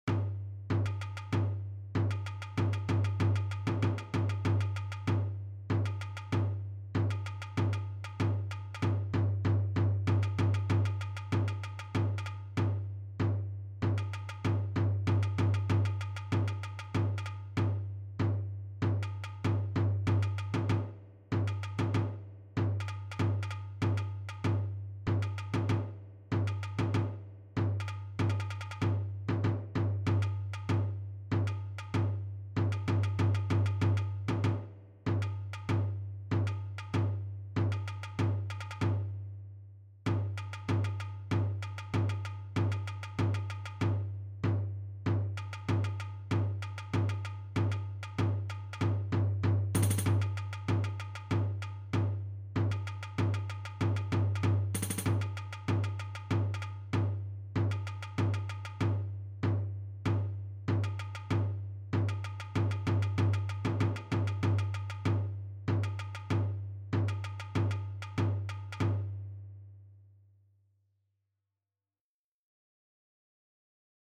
Ongestemd Marcherend Slagwerk
Snare drum Cymbals Bass drum